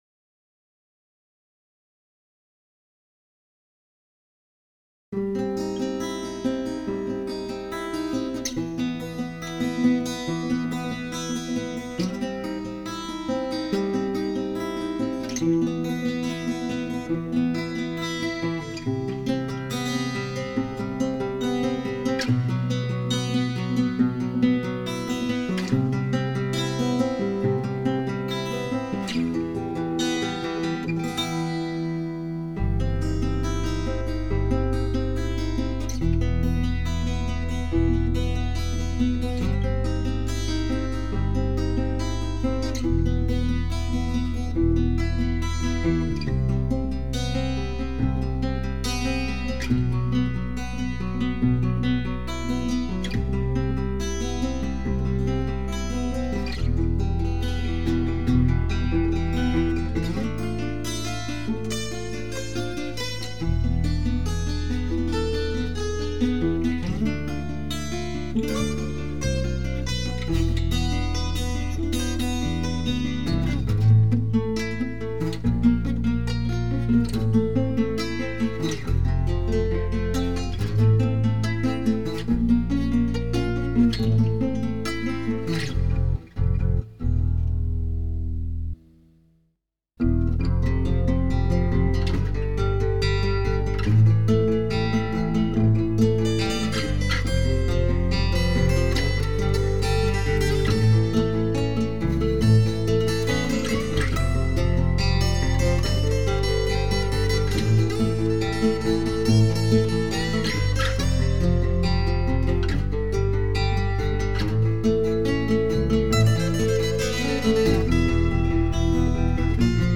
• Жанр: Металл